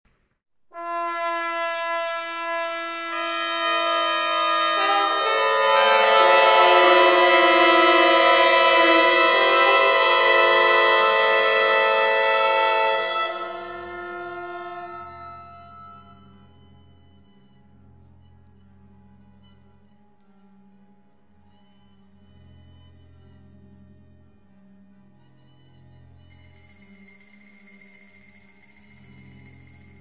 For large orchestra